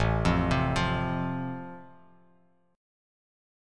Am#5 Chord